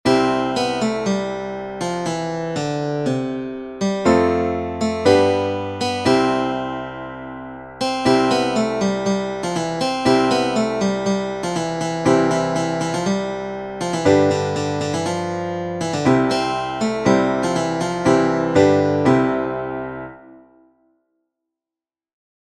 Joy_to_Guitar.mp3